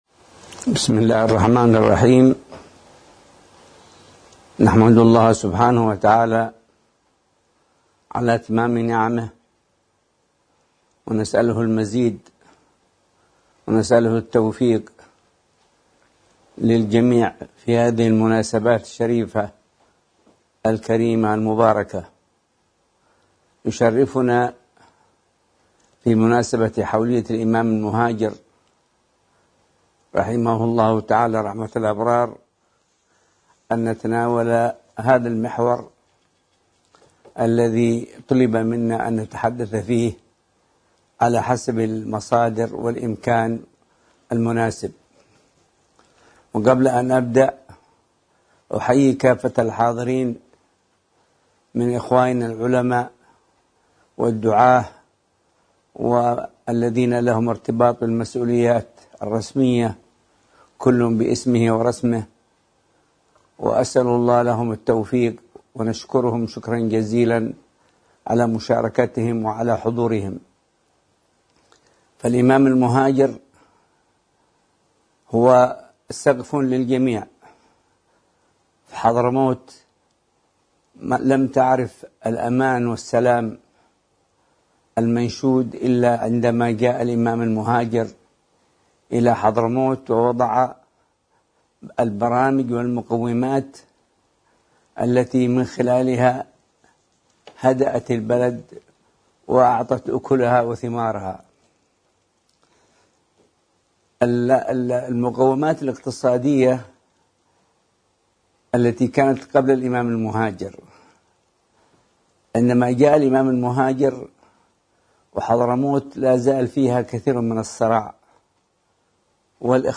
بحث ضمن فعاليات الحلقة العلمية في ذكرى دخول اﻻمام المهاجر الى حضرموت مساء الجمعة 12 محرم 1443هـ